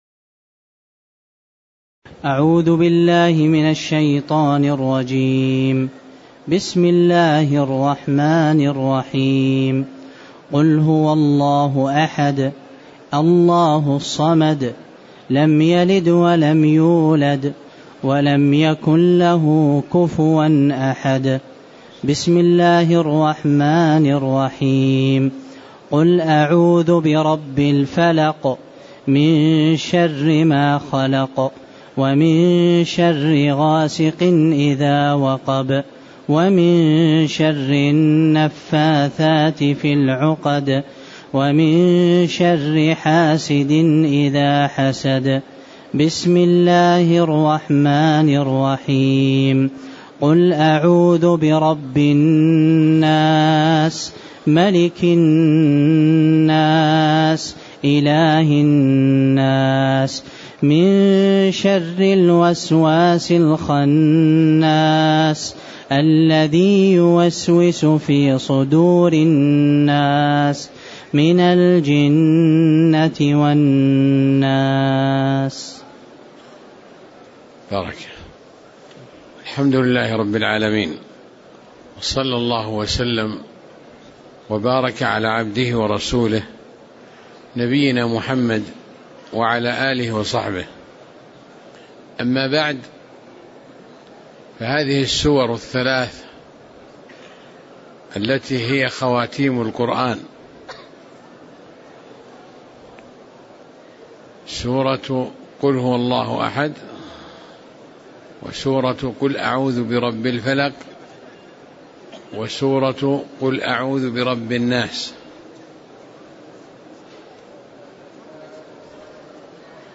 تاريخ النشر ٣ رجب ١٤٣٨ هـ المكان: المسجد النبوي الشيخ